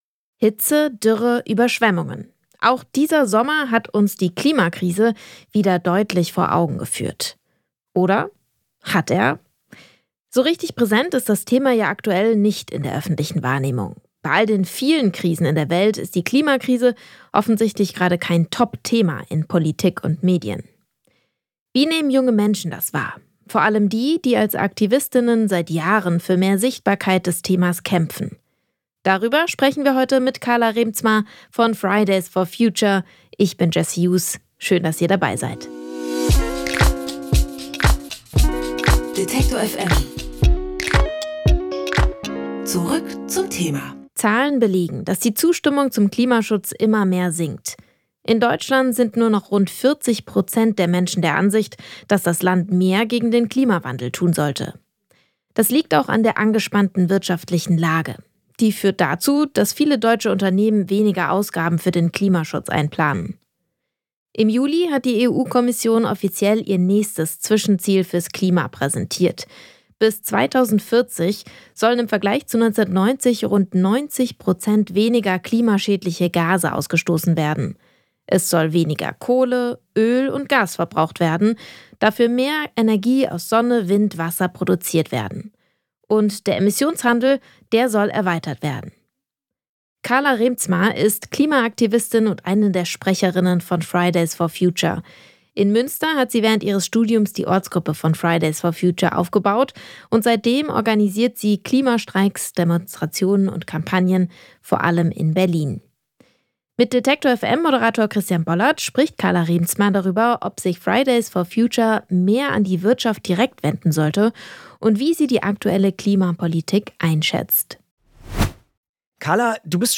Im Gespräch mit "Fridays For Future"-Sprecherin Carla Reemtsma über die aktuelle Wirtschaftspolitik und mangelnden Klimaschutz.